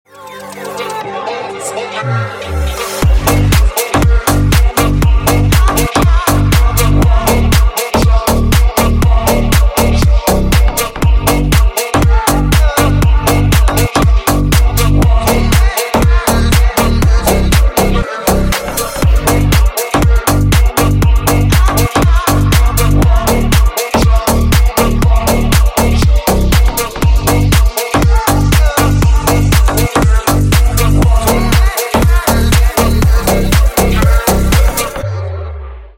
Клубные Рингтоны » # Громкие Рингтоны С Басами
Рингтоны Ремиксы » # Танцевальные Рингтоны